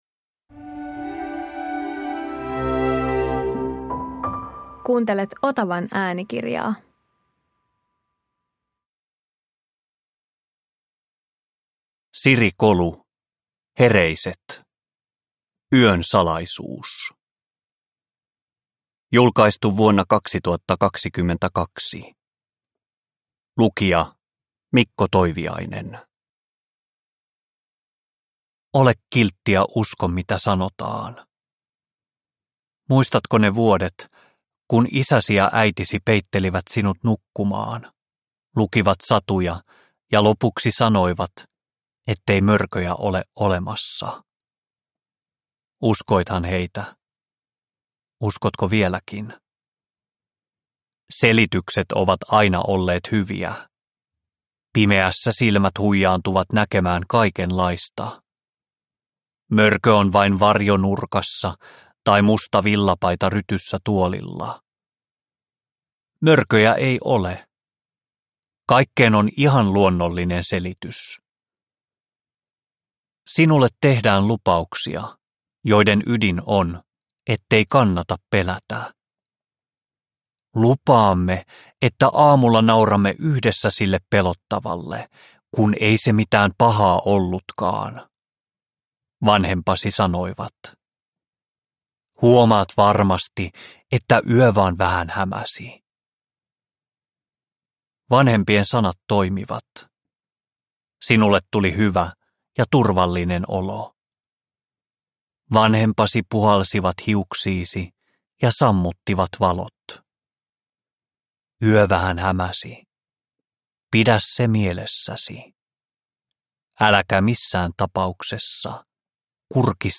Yön salaisuus – Ljudbok